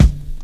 Kick (Gone).wav